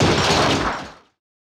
Roland.Juno.D _ Limited Edition _ GM2 SFX Kit _ 06.wav